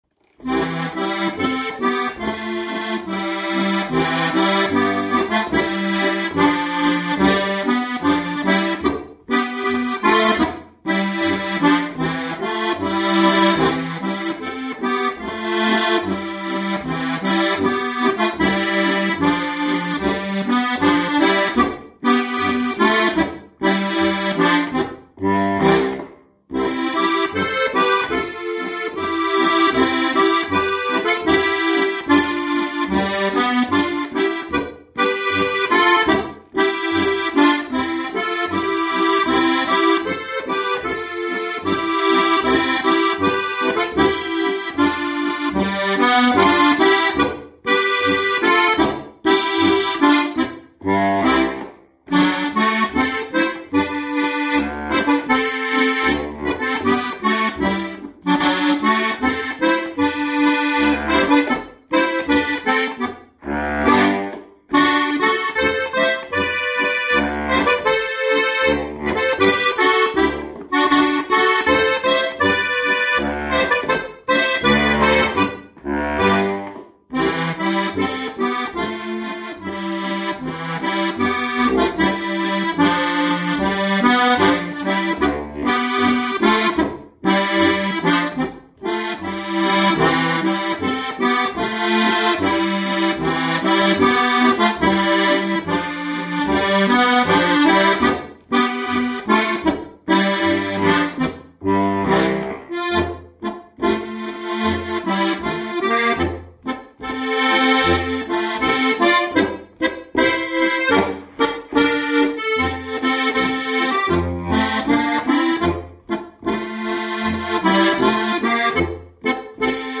Ein gemütlicher Boarischer!
• 4-Reihige Harmonika